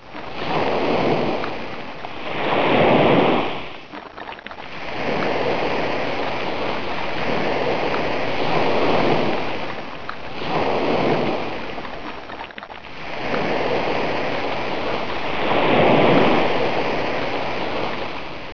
rd_waves.wav